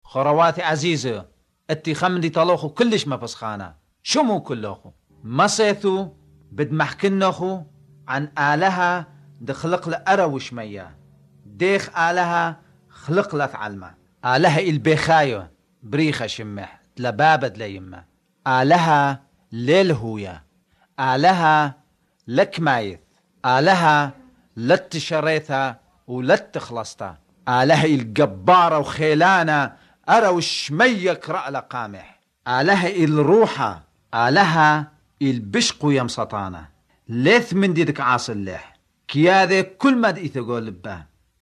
As for an Iraqi accent, all I can really tell is that the speaker often has dark, velarised /l/s (but notably, not in Aalahaa: the opposite of most Arabic dialects, where Allaah universally has emphatic /l/ but otherwise, most dialects don’t except for Iraqi and Gulf dialects).